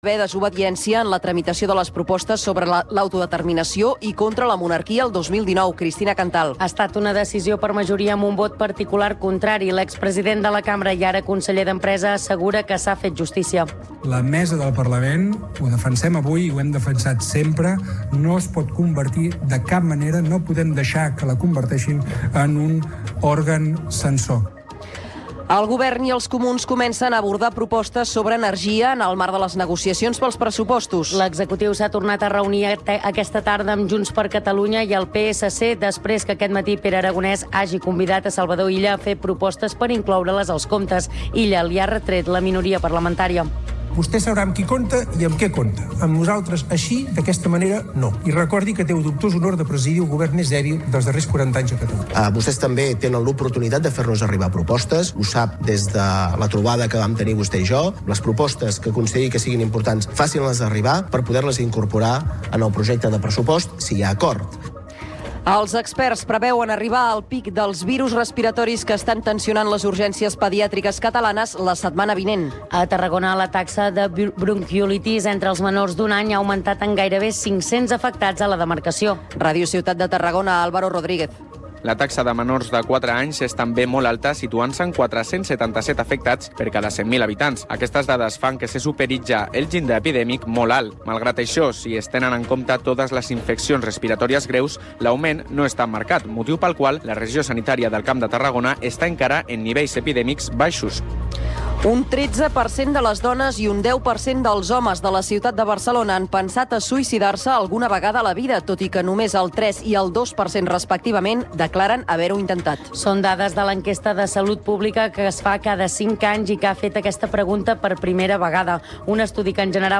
Informatiu en xarxa que fa difusió nacional dels fets locals i ofereix la visió local dels fets nacionals.